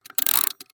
timer, short windup already running, with slight ding of bell
clock crank timer turn winding windup sound effect free sound royalty free Nature